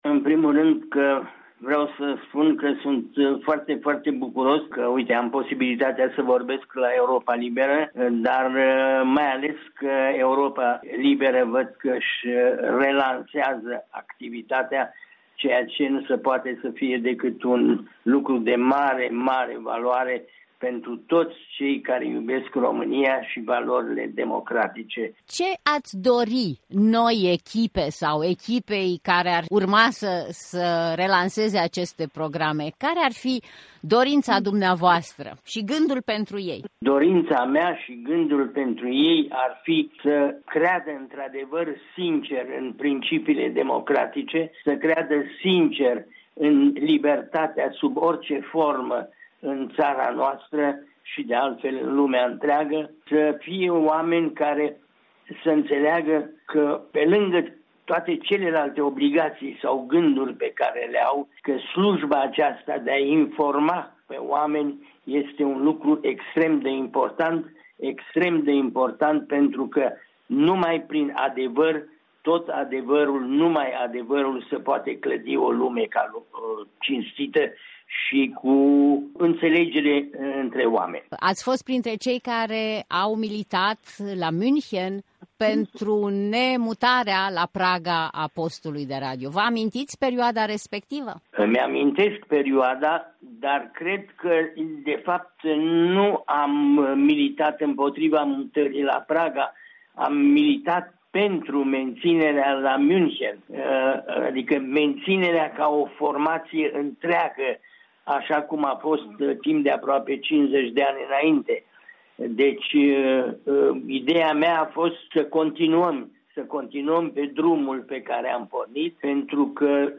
Un interviu cu un cunoscut fost director al Serviciilor românești de la Radio Europa Liberă și Vocea Americii.